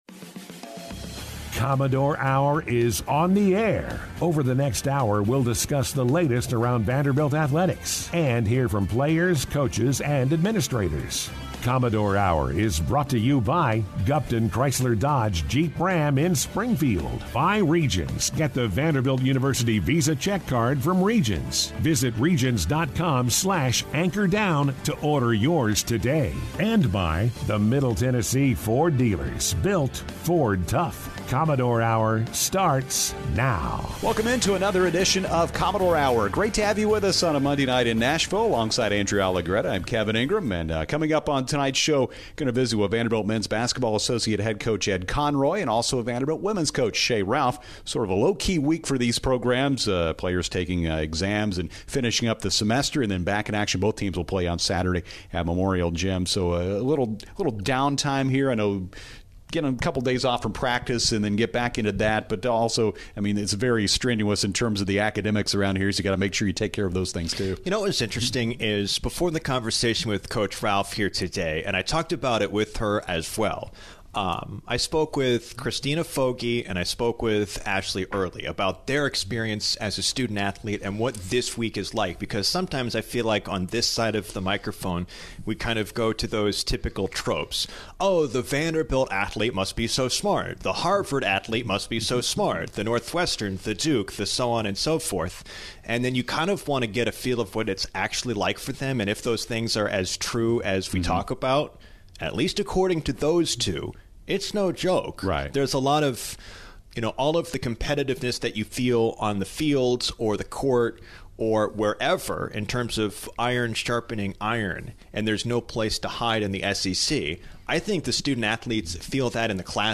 Guests on this week's Commodore Hour, Mondays from 6-7 PM on ESPN 94.9: